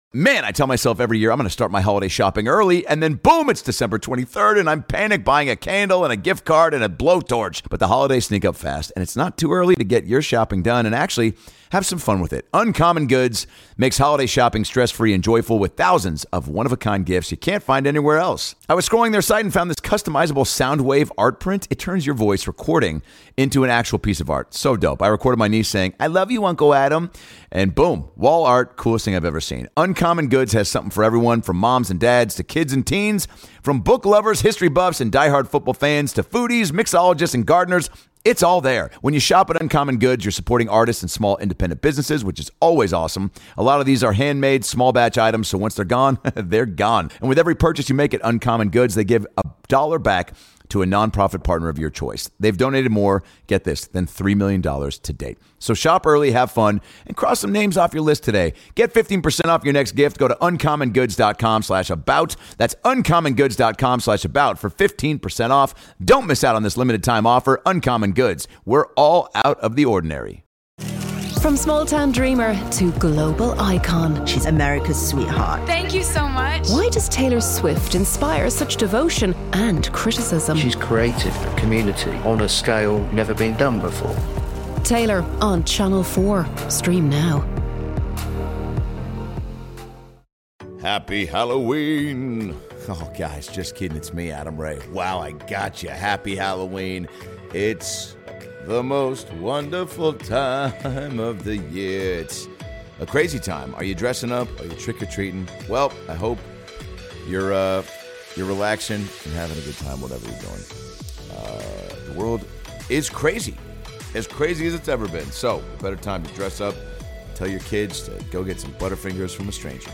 An hour of unhinged, full improvised comedy with the biggest stars, on the biggest holiday!